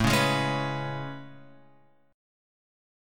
A Major 9th